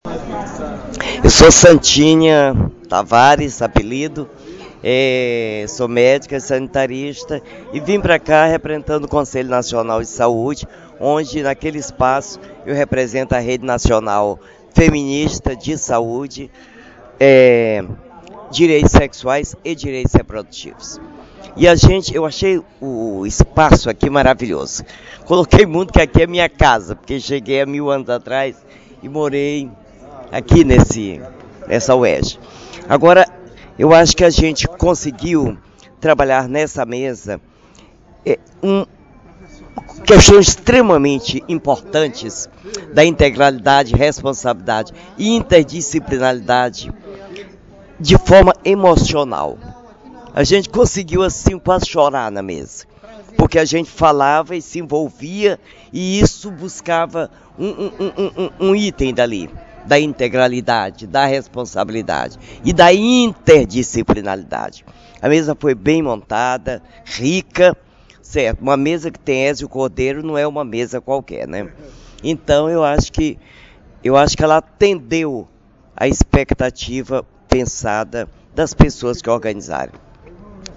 Ouça as opiniões de participantes e palestrantes sobre o Seminário 25 Anos do Direito à Saúde: Integralidade, Responsabilidade e Interdisciplinaridade – Afinal, do que se trata?.